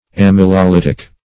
Amylolytic \Am`y*lo*ly"tic\, a. [Gr. ? starch + ? solvent; ? to